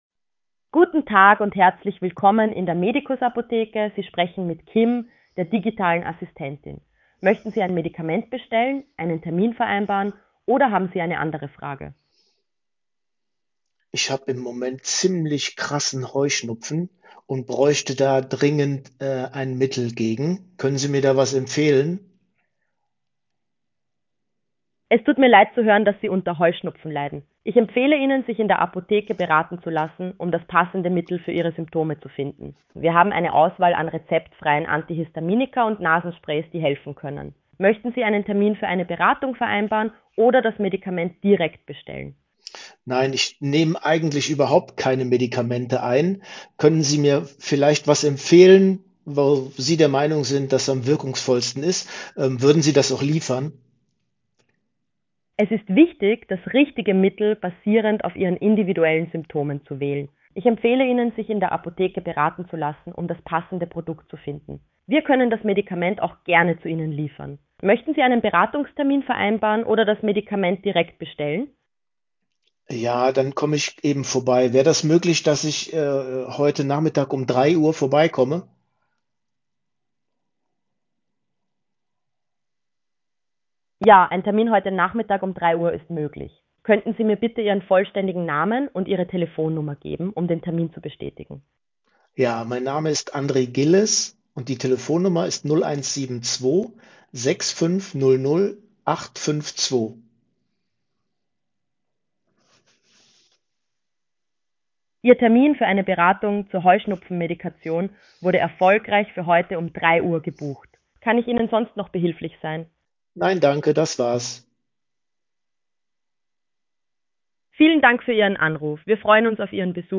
Hörprobe Anruf in der apotheke
Der KI-gestützte Voicebot „KIM“ nimmt Anrufe im Namen der „Sonnen-Apotheke“ an (optional ergänzt durch Chat auf der Website) und führt einen natürlichen Dialog: